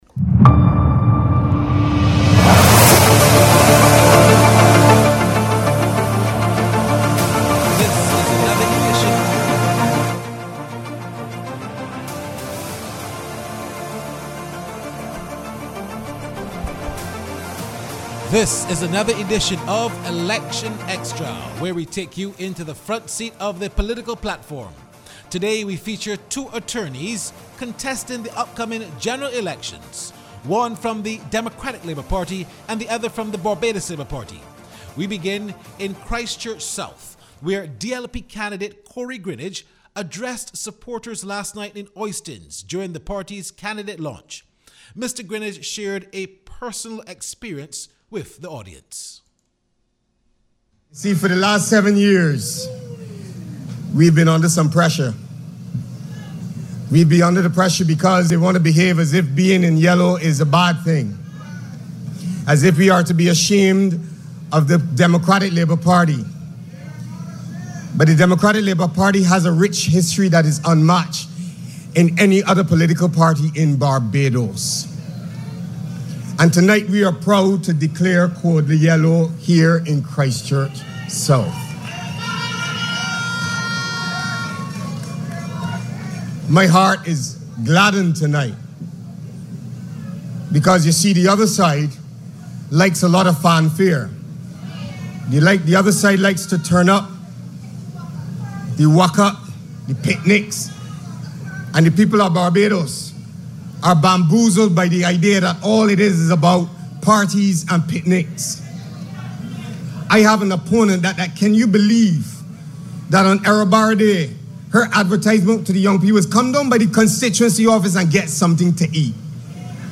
On this edition of Election Xtra, the programme focused on voices from the political platform as the general election campaign continues. The segment featured two attorneys contesting seats for different political parties